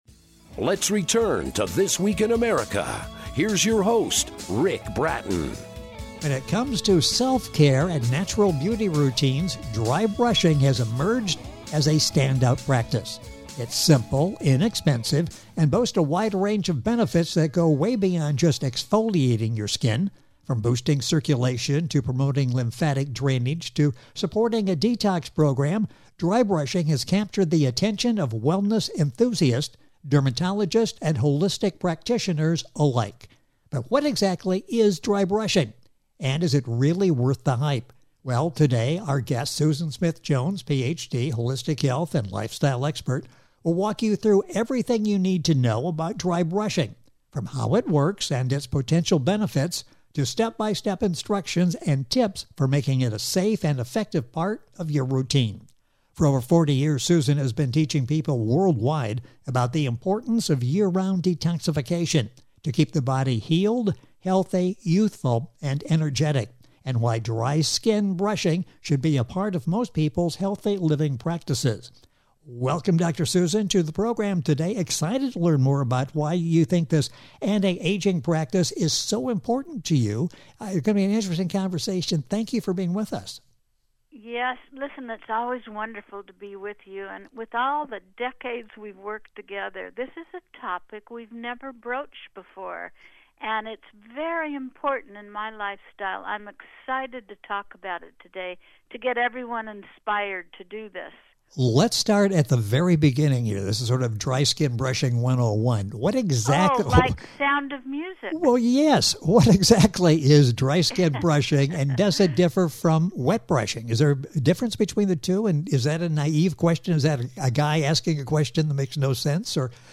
Detox Interview